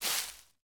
Minecraft Version Minecraft Version 1.21.5 Latest Release | Latest Snapshot 1.21.5 / assets / minecraft / sounds / block / leaf_litter / step1.ogg Compare With Compare With Latest Release | Latest Snapshot
step1.ogg